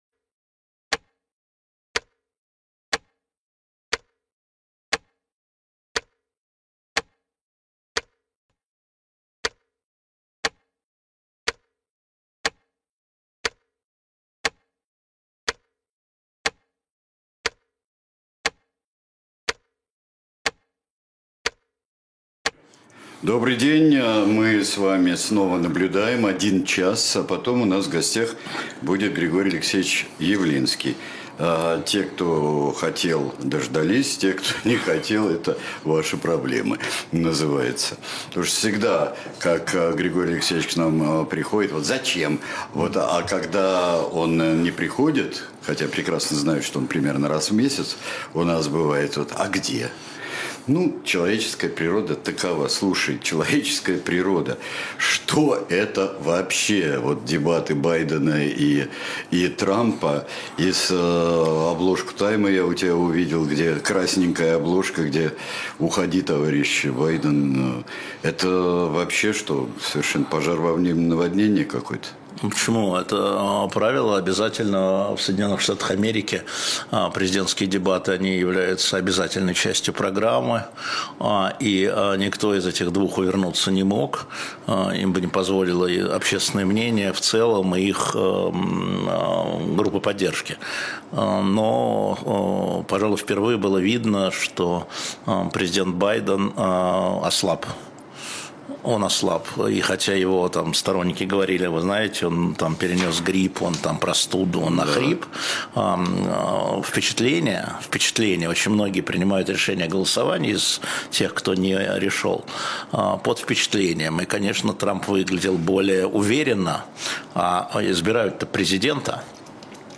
Эфир ведёт Сергей Бунтман